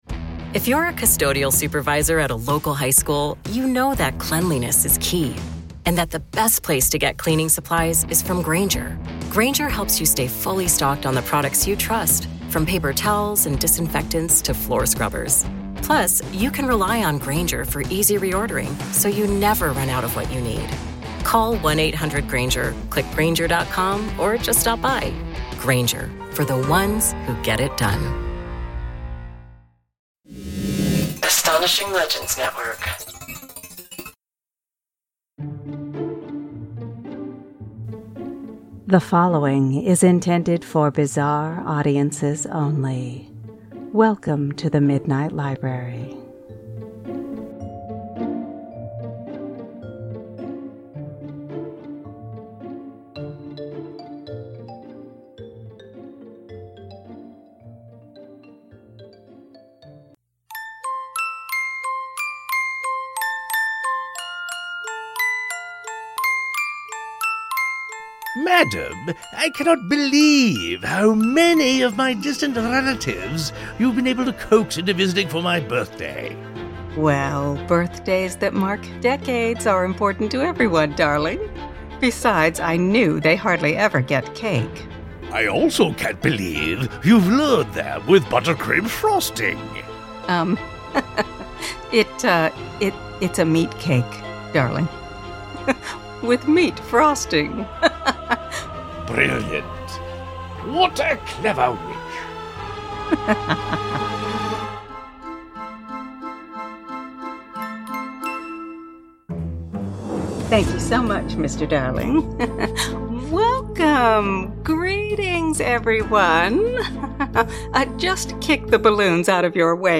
To celebrate (and salivate), tonight's reading will be all about everyone's favorite Cajun werewolf, The Rougarou!